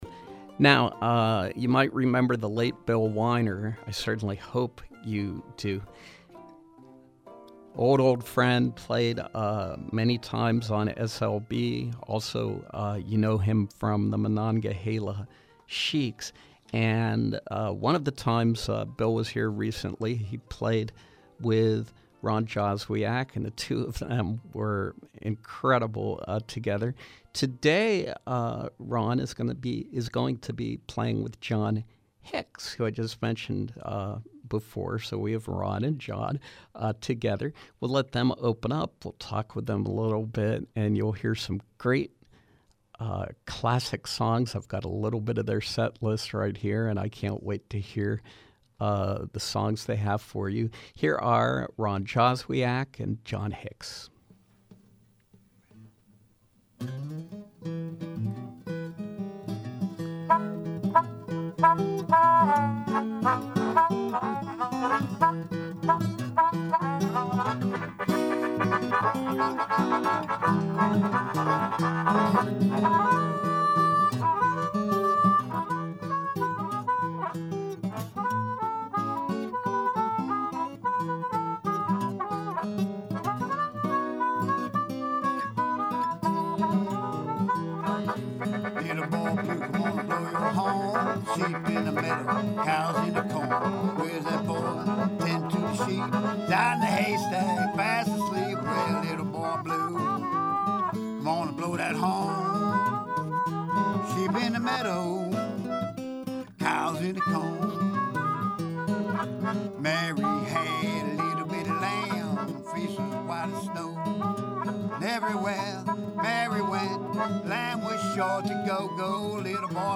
Live Performance
Blues and ragtime
guitar
harmonica